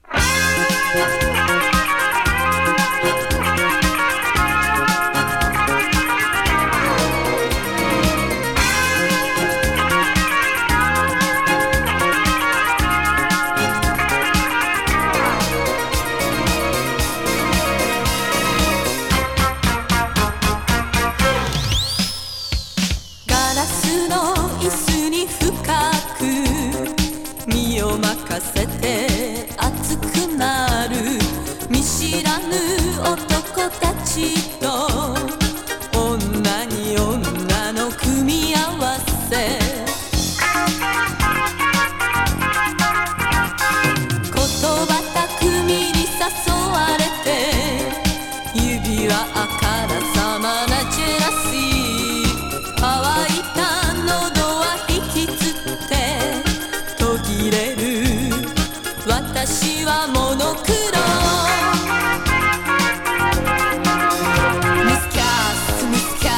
シンセ・ロッキン・ディスコ